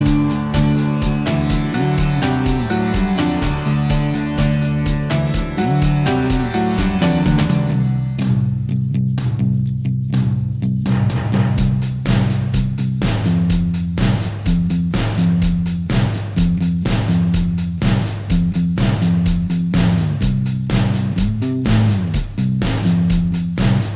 Minus Guitars Rock 3:14 Buy £1.50